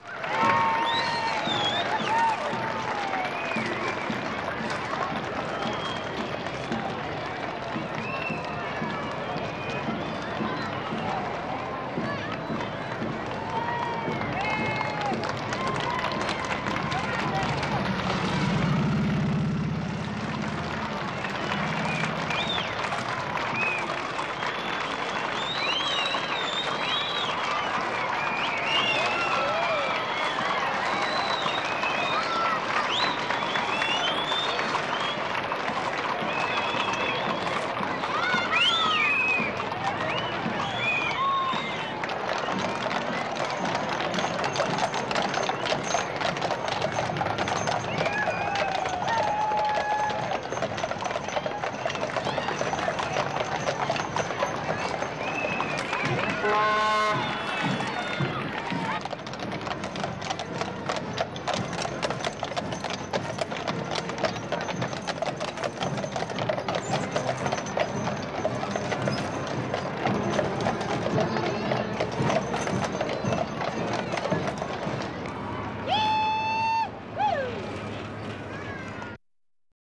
parade-ambience-with-cheering-applauding-and-whistling-crowd